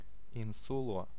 Ääntäminen
UK : IPA : /ˈaɪ̯lənd/ US : IPA : /ˈaɪ̯lənd/